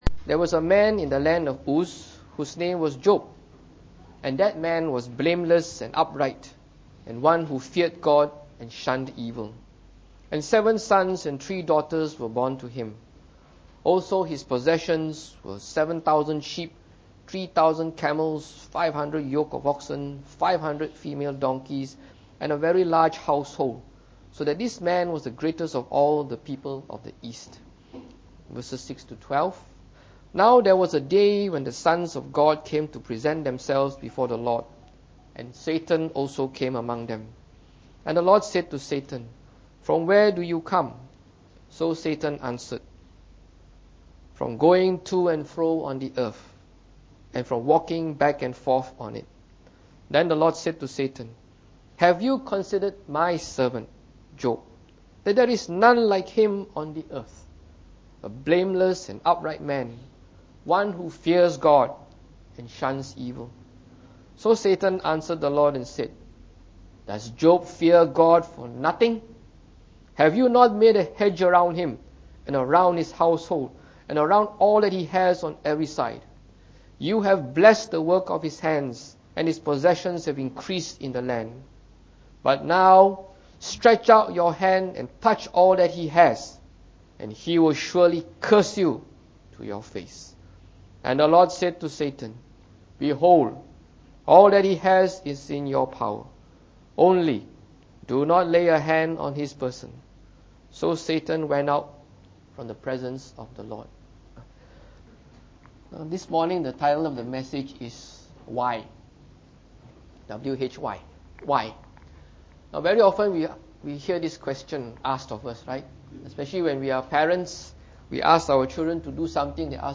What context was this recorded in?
Preached on the 6th of March 2016.